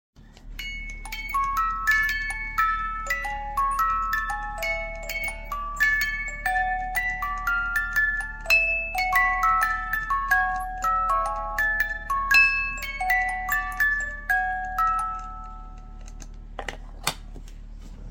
Cajita musical de manivela